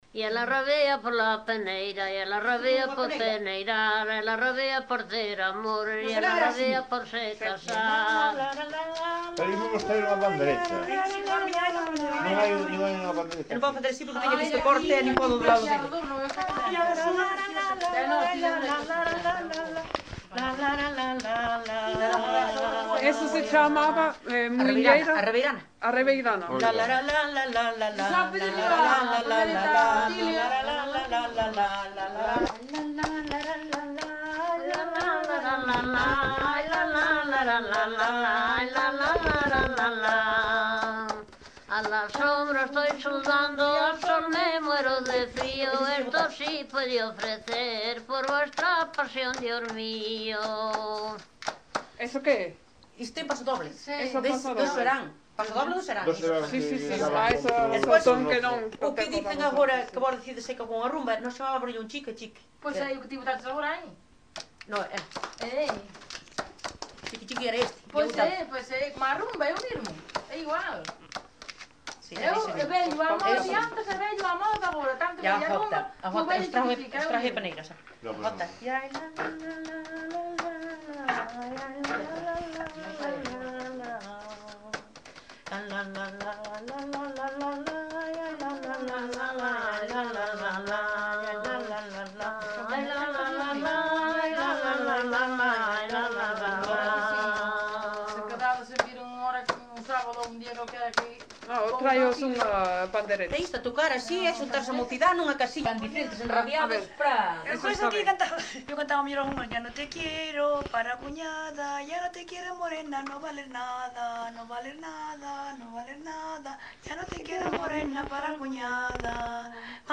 Tipo de rexistro: Musical
Soporte orixinal: Casete
Datos musicais Refrán
Instrumentación: Voz
Instrumentos: Voces femininas